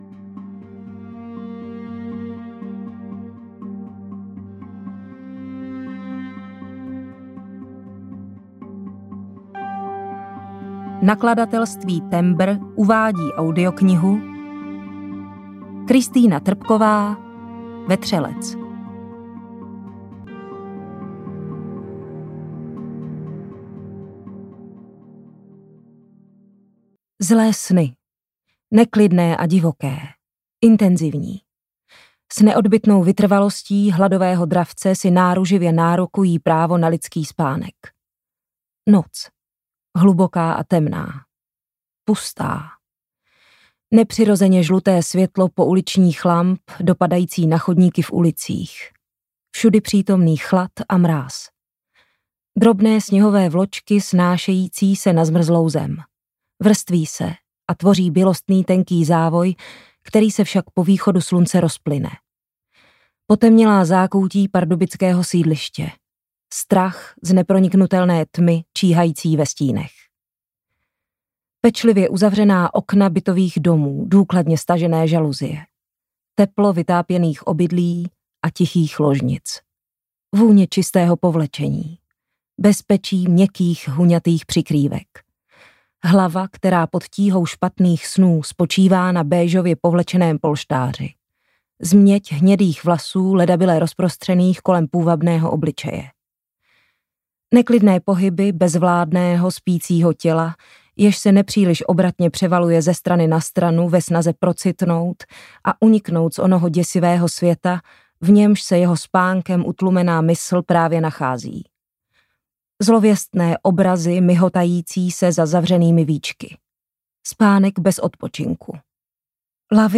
Vetřelec audiokniha
Ukázka z knihy
• InterpretZuzana Kajnarová
vetrelec-audiokniha